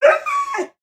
Minecraft Version Minecraft Version snapshot Latest Release | Latest Snapshot snapshot / assets / minecraft / sounds / mob / wolf / sad / bark2.ogg Compare With Compare With Latest Release | Latest Snapshot
bark2.ogg